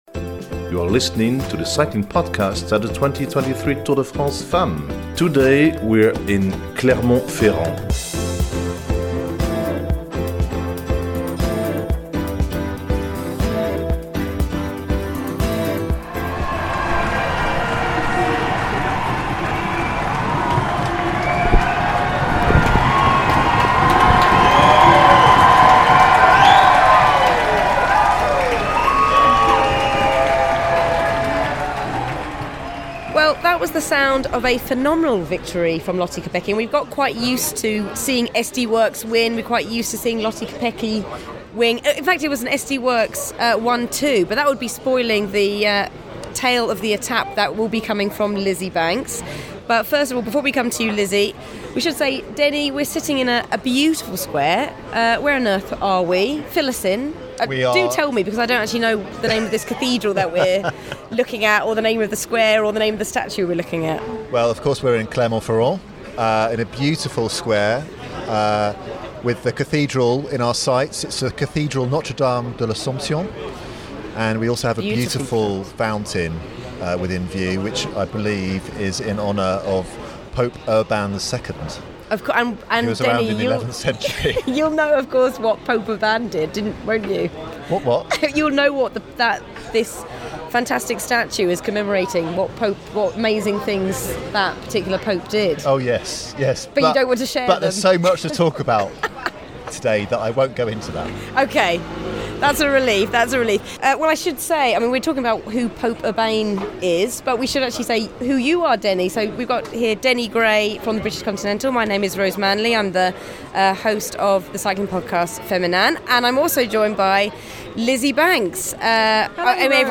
The team analyse a thrilling Stage 1 and pick out the winners and losers at this early stage. In true Cycling Podcast fashion, we leave the speculation to someone else - this time the riders themselves - including defending champion Annemiek Van Vleuten - as we hear what they expect from the week of racing.